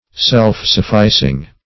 Self-sufficing \Self`-suf*fi"cing\, a.